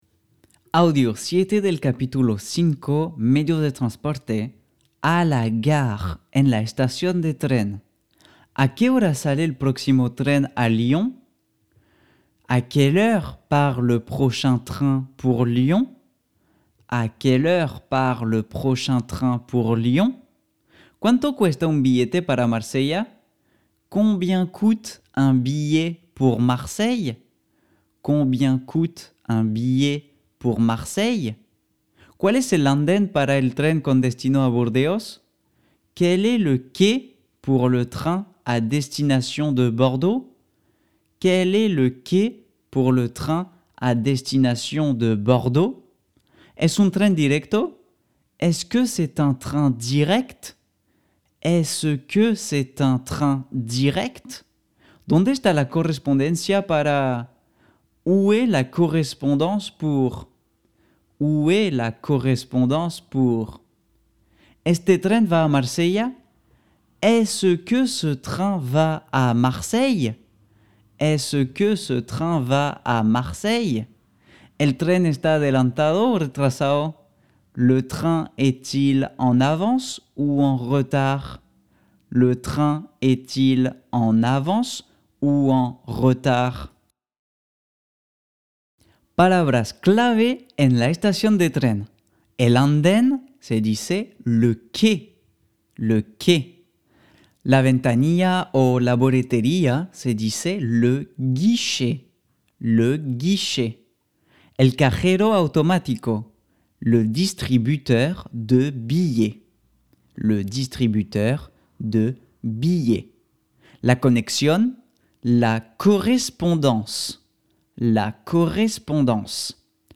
Audios incluidos para mejorar tu pronunciación con la voz de un nativo.